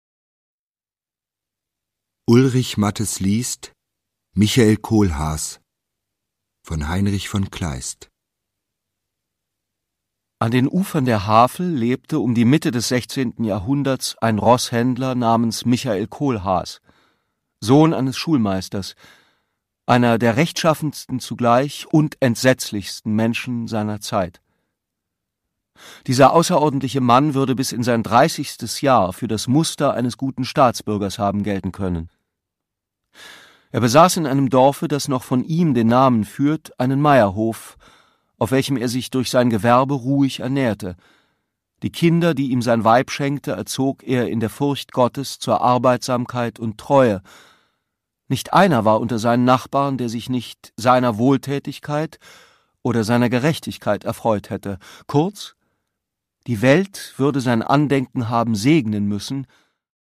Heinrich von Kleist: Michael Kohlhaas (Ungekürzte Lesung)
Produkttyp: Hörbuch-Download
Gelesen von: Ulrich Matthes